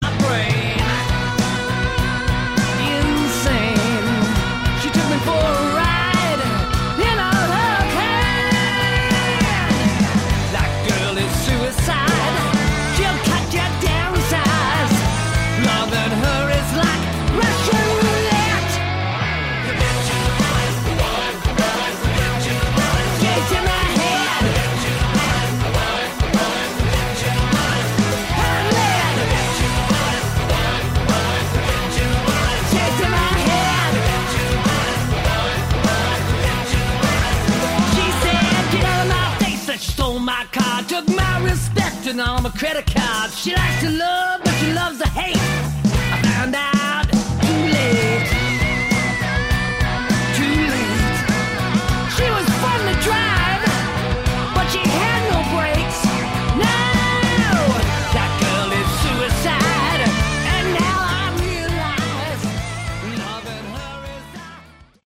Category: Hard Rock